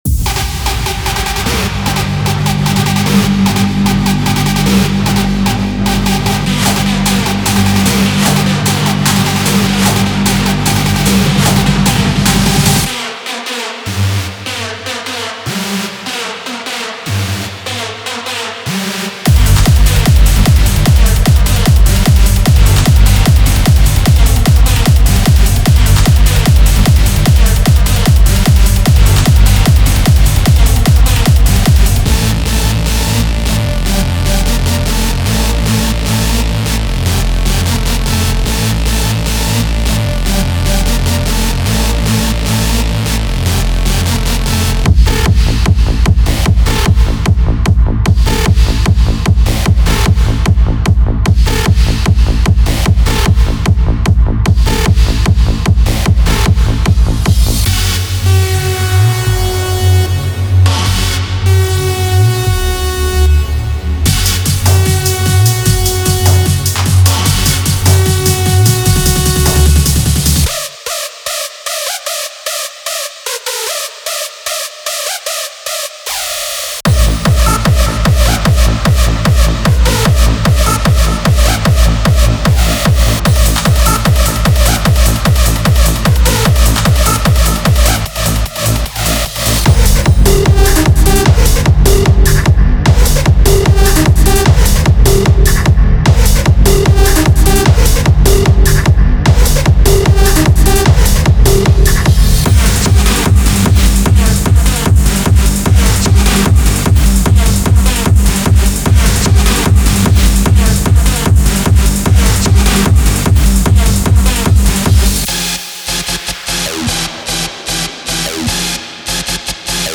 Genre:Hard Dance
すべてのループは150BPMに固定されており、最大限の一貫性と純粋で生々しいパワーを提供します。
デモサウンドはコチラ↓